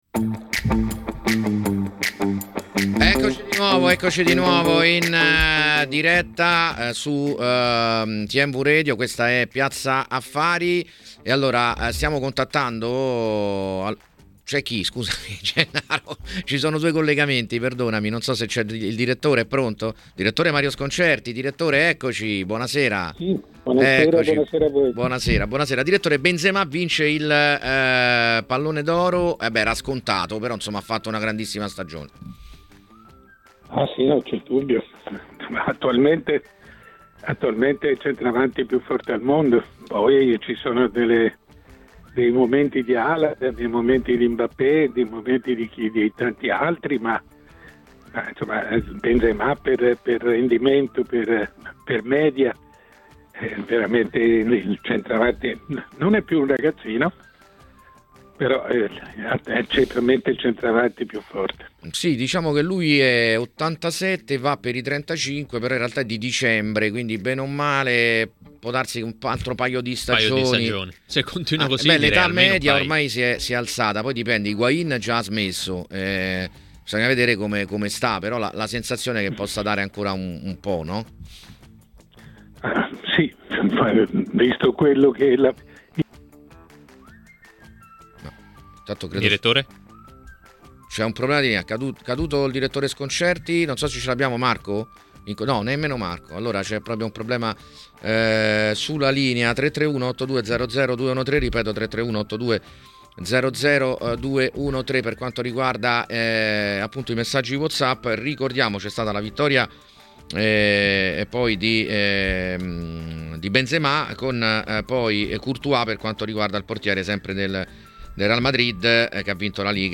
Ascolta l'audio Il direttore Mario Sconcerti è intervenuto durante 'Piazza Affari', in onda su Tmw Radio , per analizzare i temi del giorno.